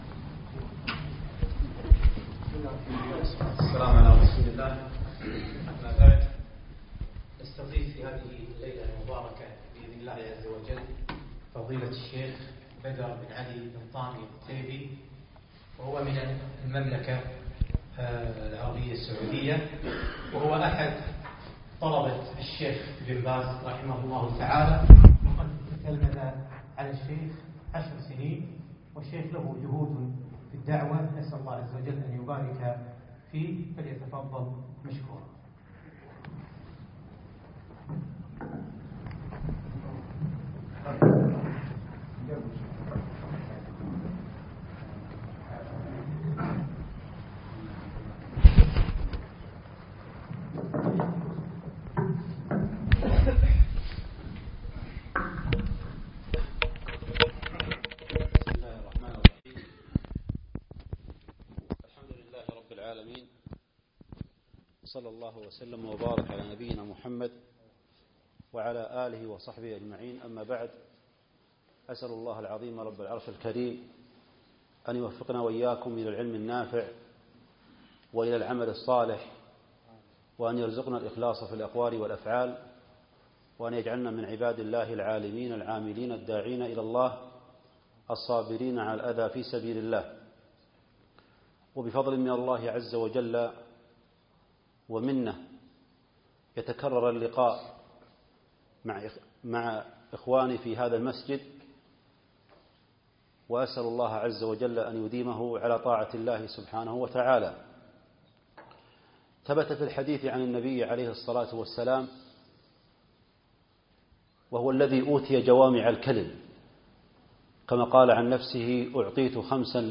بعد صلاة المغرب يوم الأحد 5 4 2015 في دار القرآن الرميثية